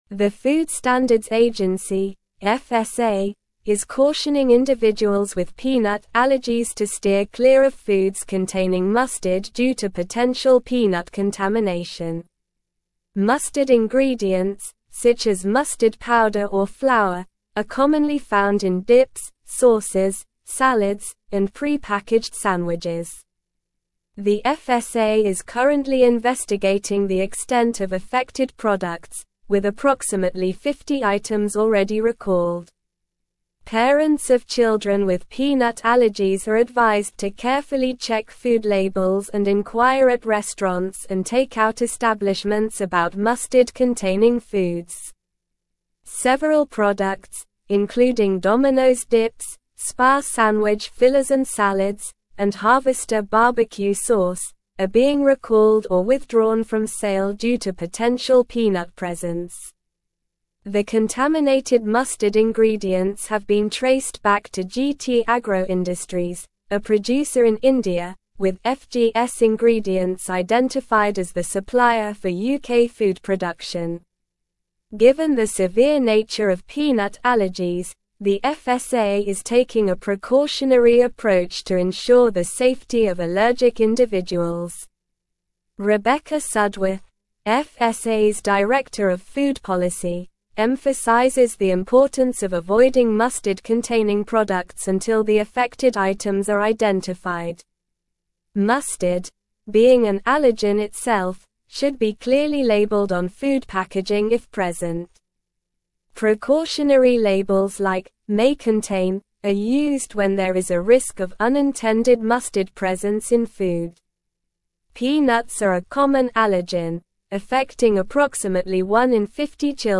Slow
English-Newsroom-Advanced-SLOW-Reading-FSA-Warns-of-Peanut-Contamination-in-Mustard-Products.mp3